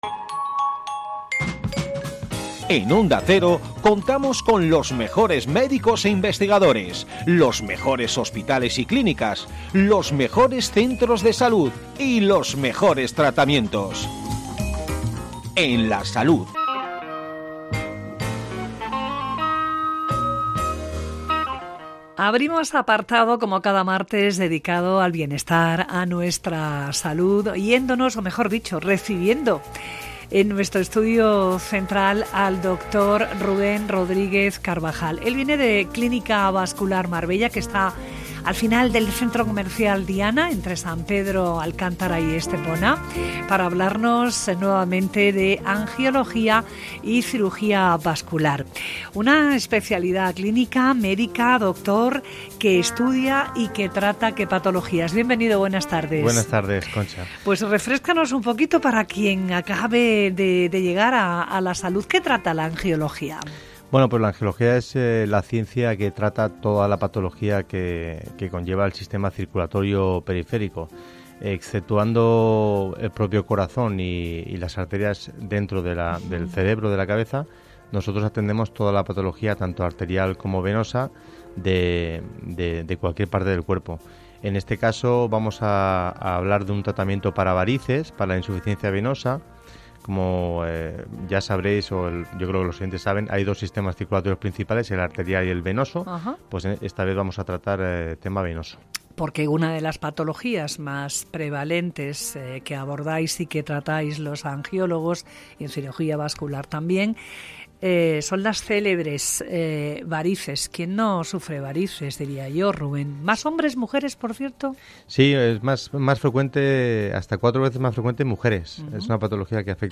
Tratamiento innovador y no invasivo para las varices. Entrevista